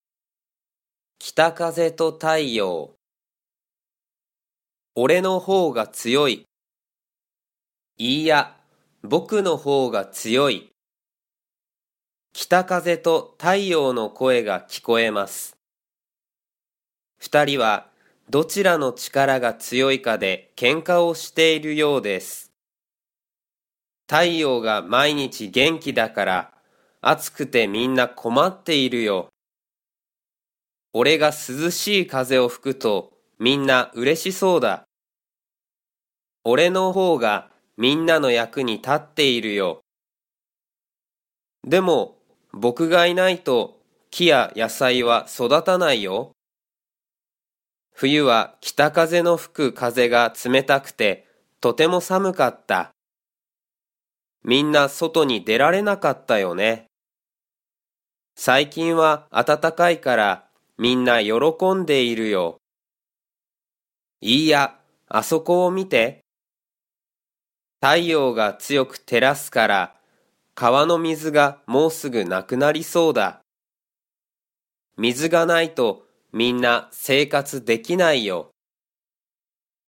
Japanese Graded Readers: Fairy Tales and Short Stories with Read-aloud Method
Natural Speed